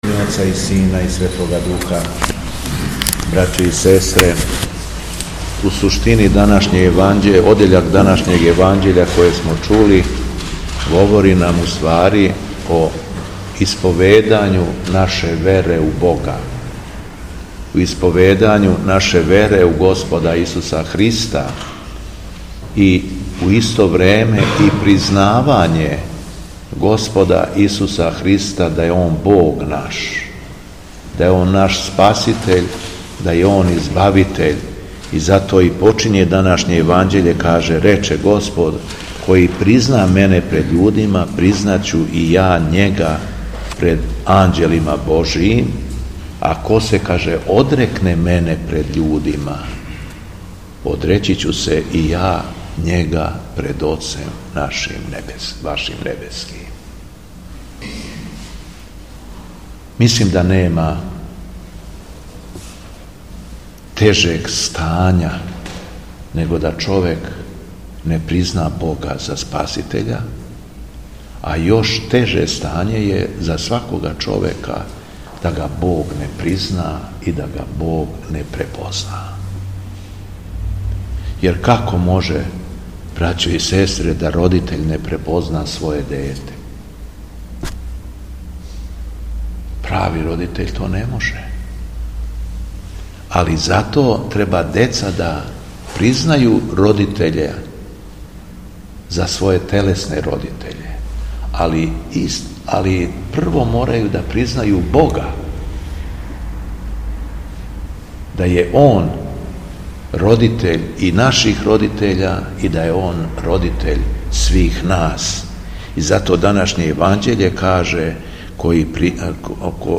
Беседа Његовог Високопреосвештенства Митрополита шумадијског г. Јована
Након прочитаног јеванђељског штива верном народу се надахнутим словом обратио Митрополит Јован: